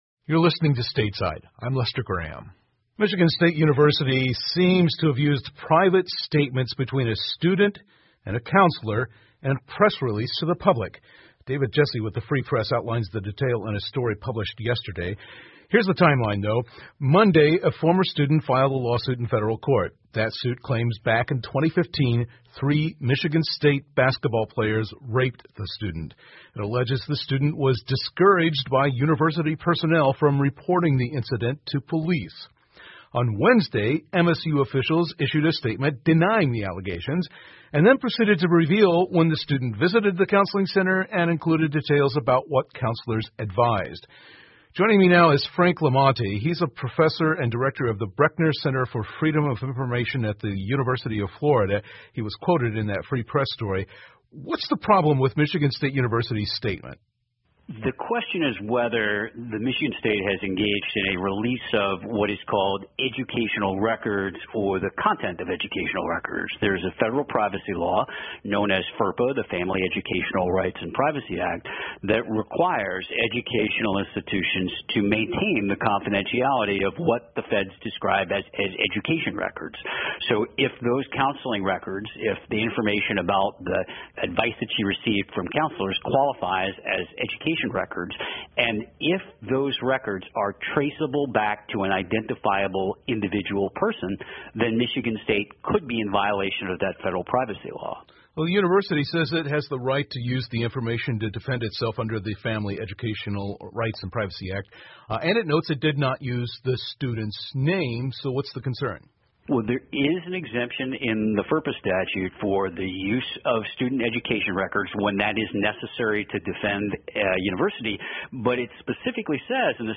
密歇根新闻广播 MSU使用隐私作为"剑和盾牌" 听力文件下载—在线英语听力室